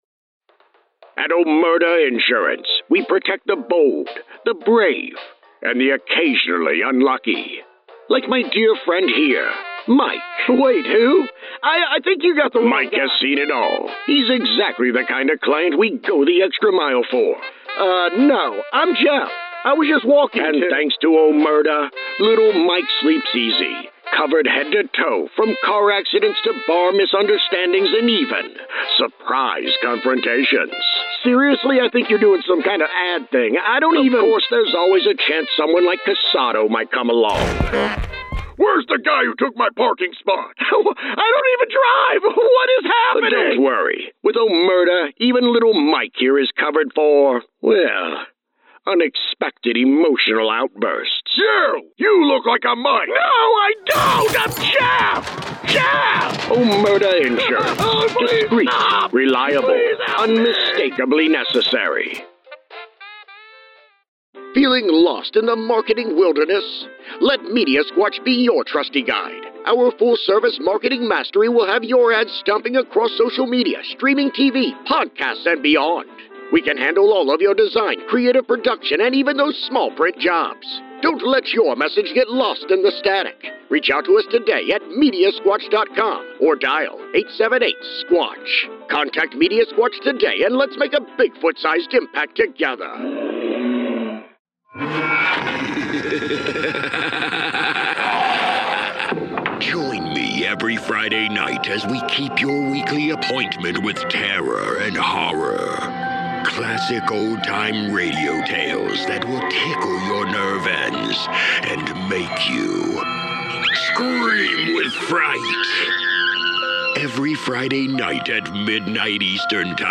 Demos that feature actual work
Vintage-Style-Ads-14.mp3